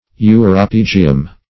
Uropygium \U`ro*pyg"i*um\, n. [NL., fr. Gr.